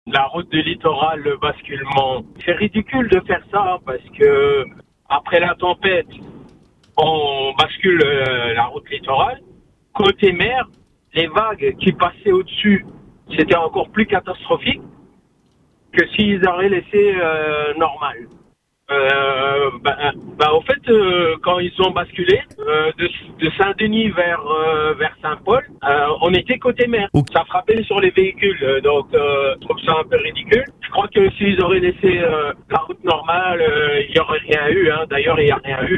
Une situation qui interroge et inquiète certains automobilistes, à commencer par celui que vous allez entendre.